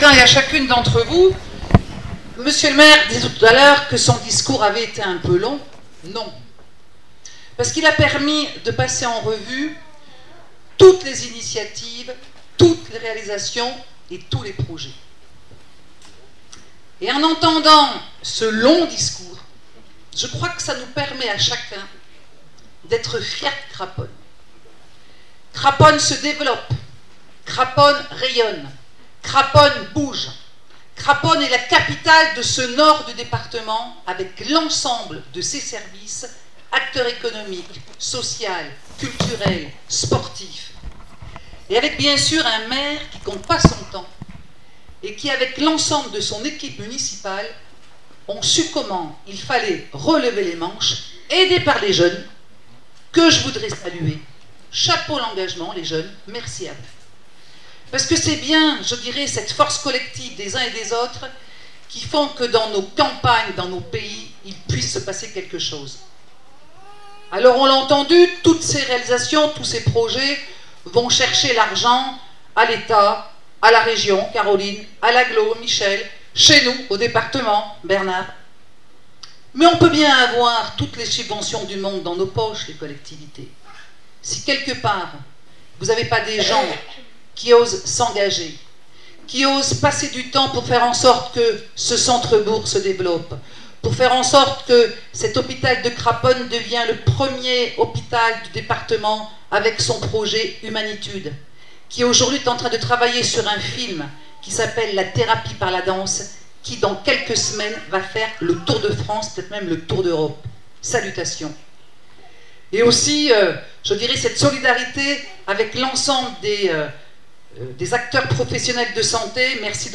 vœux du maire de Craponne sur arzon 2023 Laurent Mirmand
Marie Agnès Petit presidente conseil departemental
MARIE-AGNES-PETIT-0.mp3